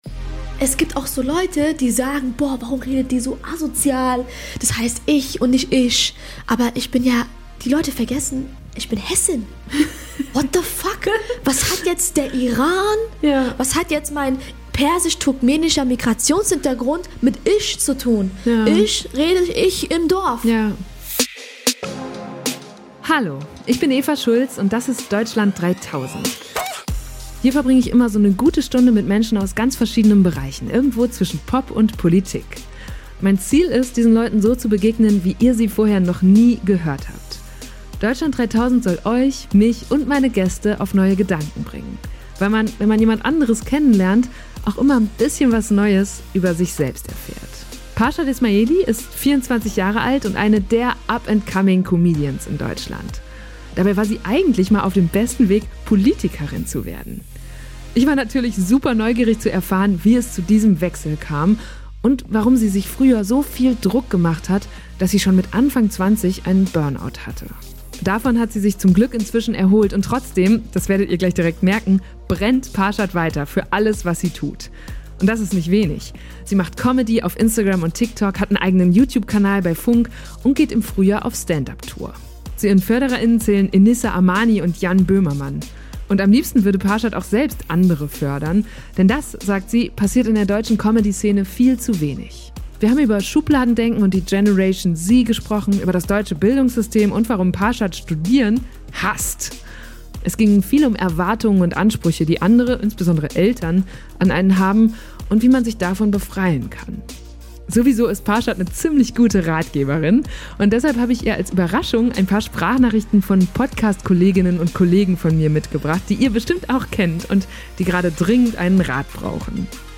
Wir haben viel gelacht, auch mehrmals gesungen, und eine neue Vokabel gelernt: nämlich "Ataraxie".